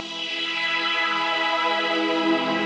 SaS_MovingPad03_90-E.wav